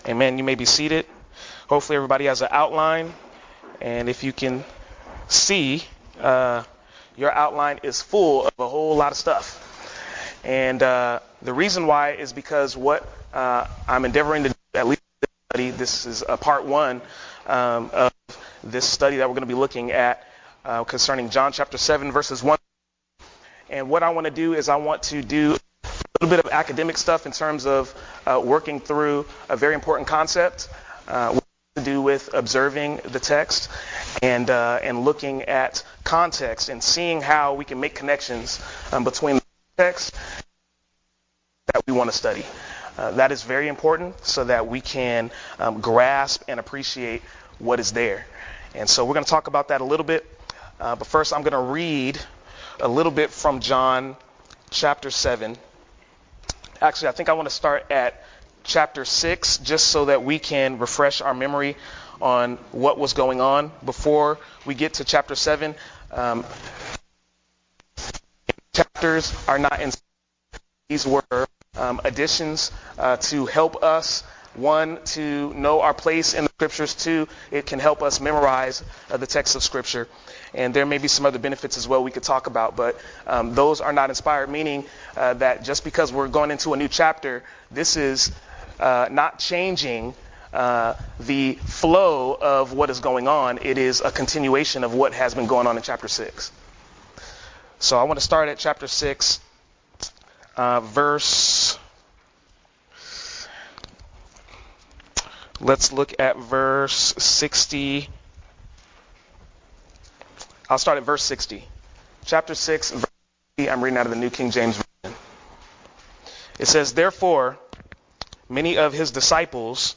Bible Study